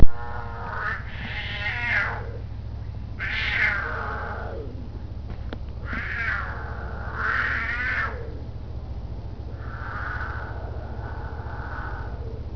Puma at the zoo, yowling